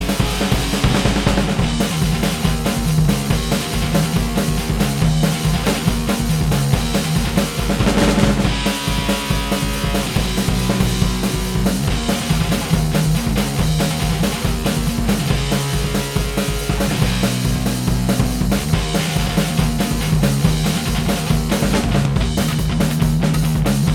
Minus All Guitars Rock 2:45 Buy £1.50